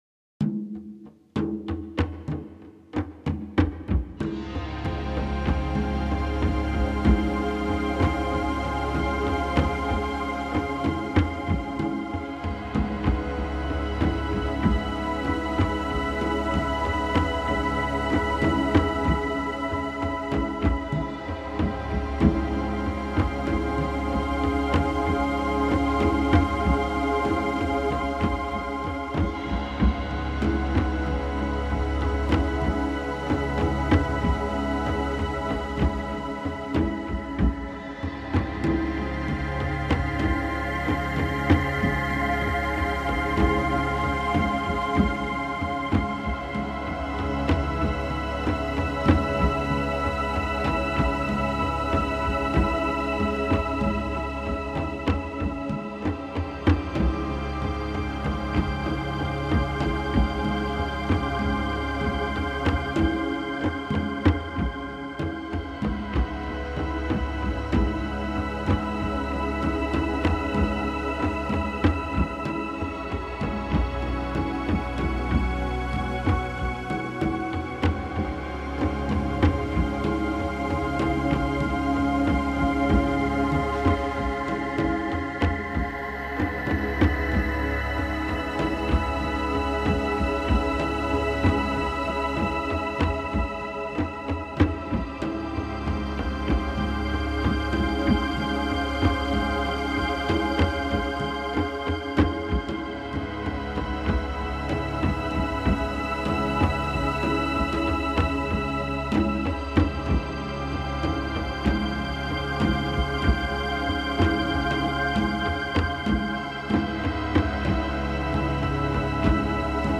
音乐类别：自然冥想
即使是两重音,而且彼此非常独立,却依然让人陶醉。